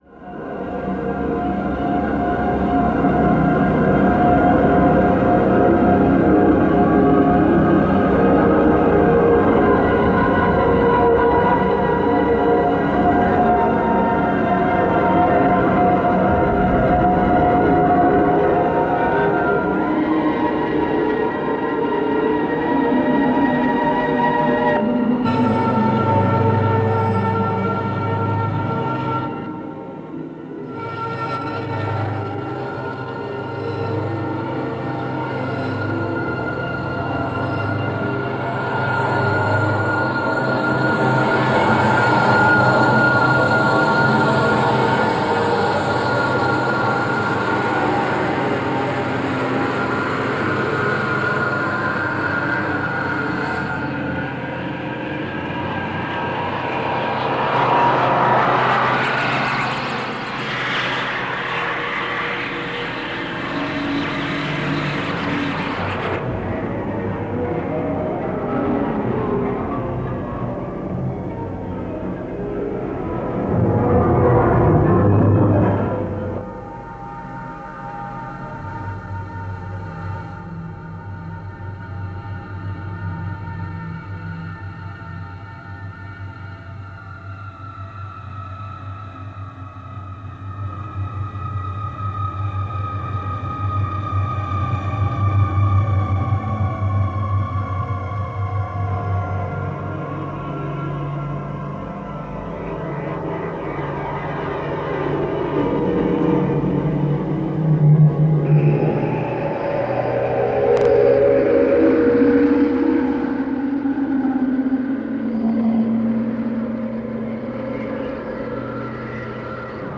longform electroacoustic composition